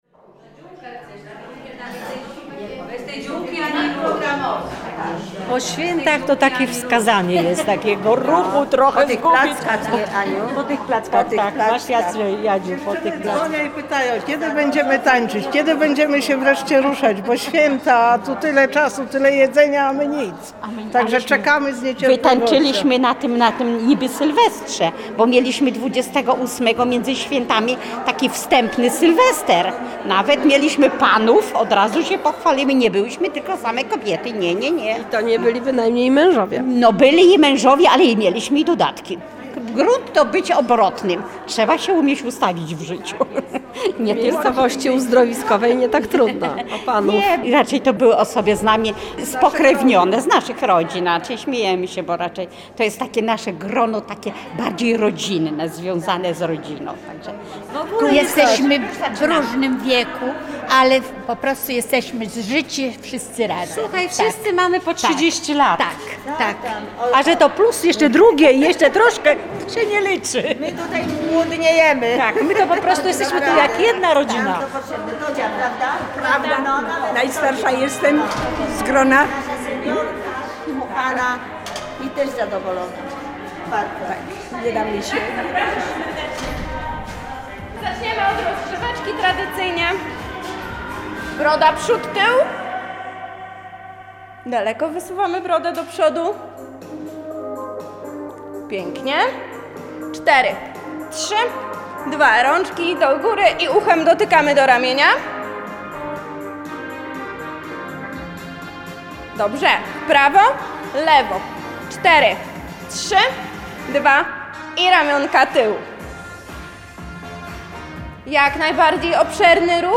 KALEJDOSKOP REGIONALNY Reportaż
karnawal_seniorek-1.mp3